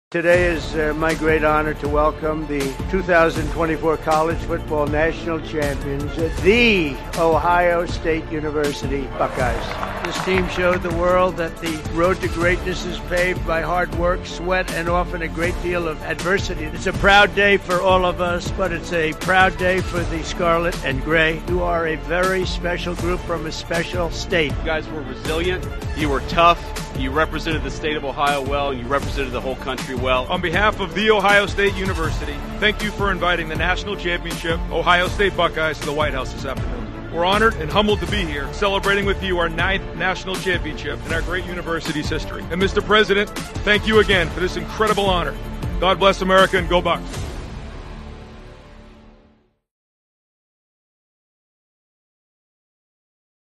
The 2024 College Football National Champions the Ohio State University Buckeyes Visit The White House